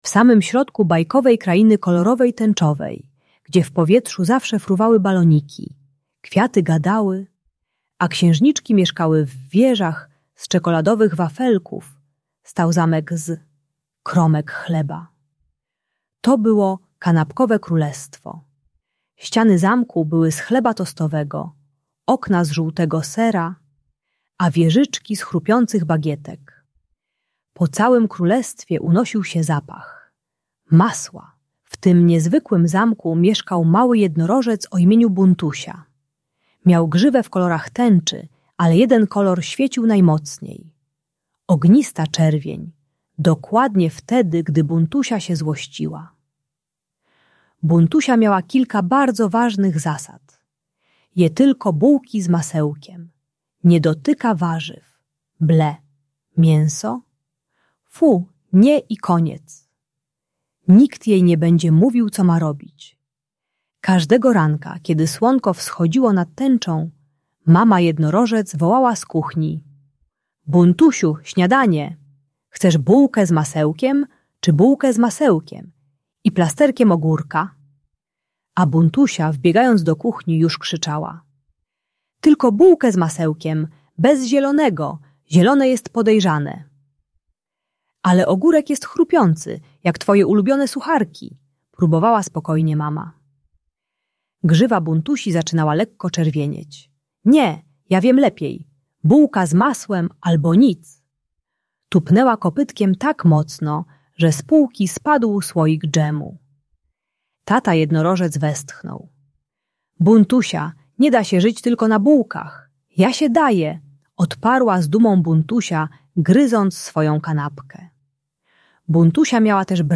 Bajka dla dziecka które nie chce jeść i ma problemy z jedzeniem, przeznaczona dla przedszkolaków w wieku 3-6 lat. Audiobajka o wybiórczym jedzeniu uczy techniki "trzech małych prób" - dotknij, spróbuj mikrokęs, wyraź emocje bez krzyku.